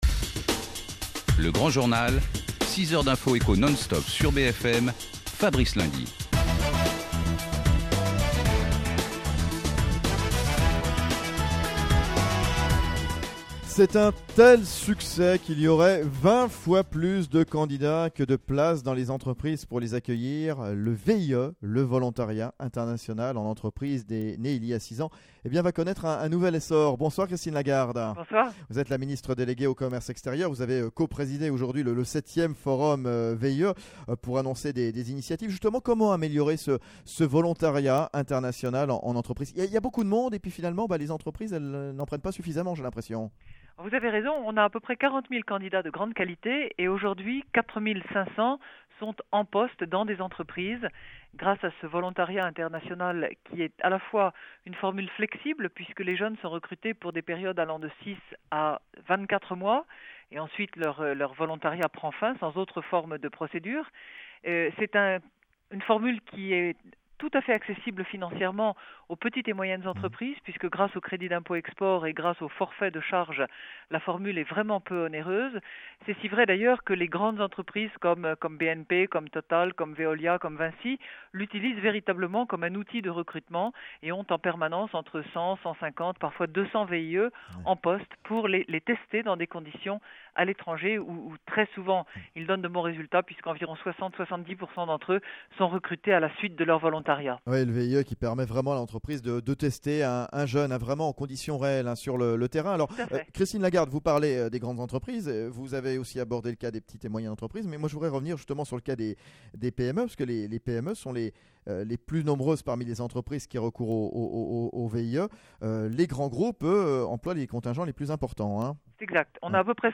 Logobfm_4 Et puis une petite interview de Christine Lagarde, ministre déléguée au Commerce extérieur, sur BFM.